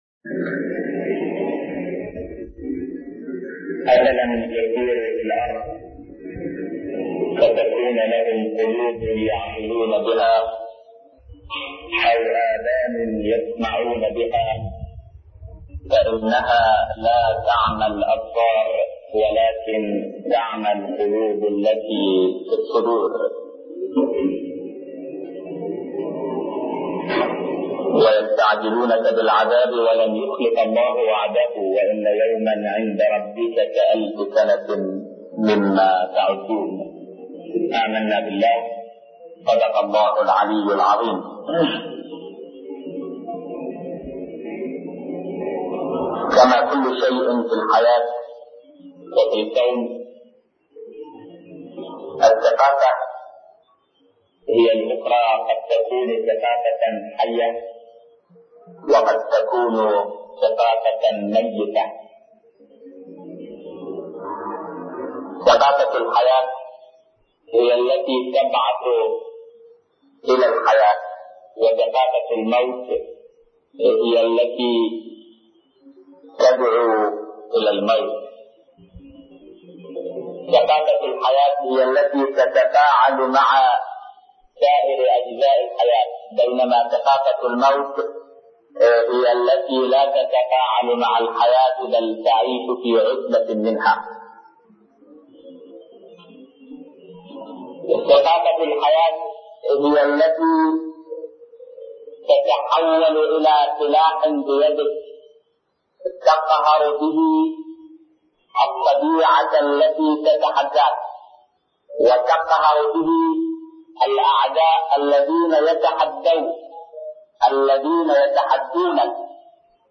محاضرات متفرقة